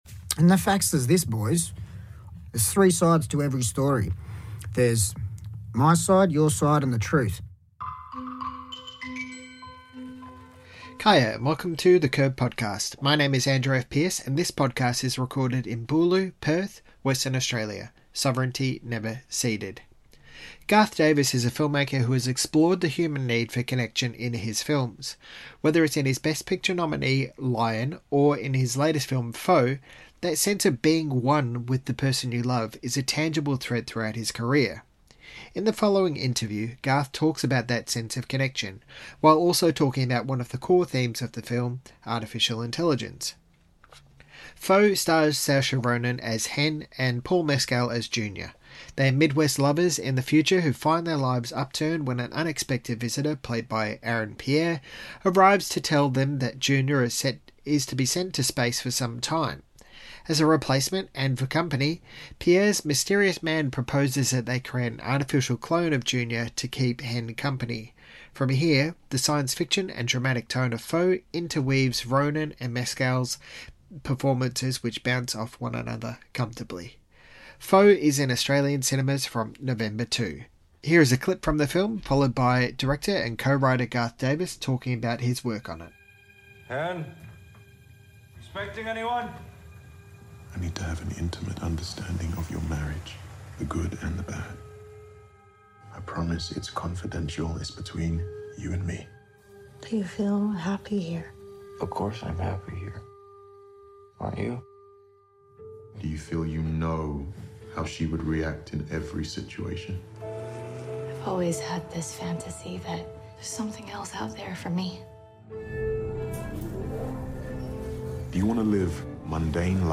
In the following interview, Garth talks about that sense of connection, while also talking about one of the core themes of the film: Artificial Intelligence.